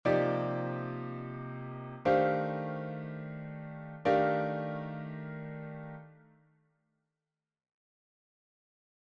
Improvisation Piano Jazz
Construction d’accords avec des quartes